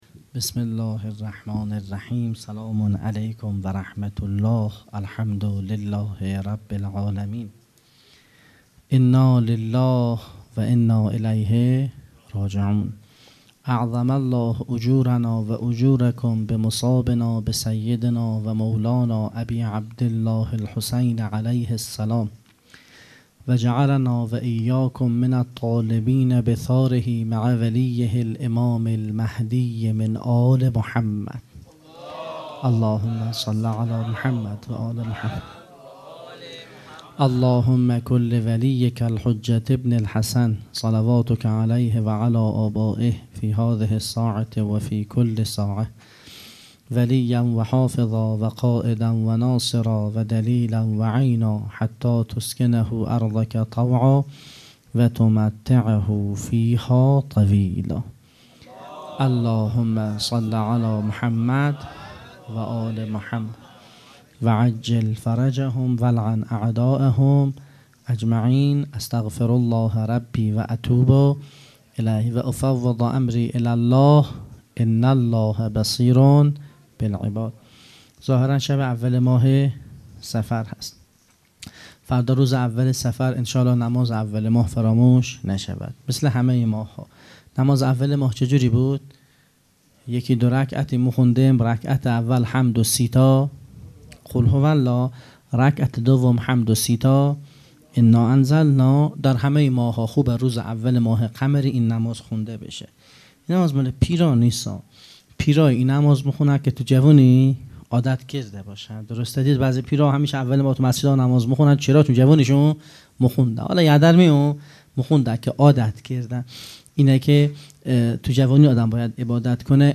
خیمه گاه - هیئت مکتب الزهرا(س)دارالعباده یزد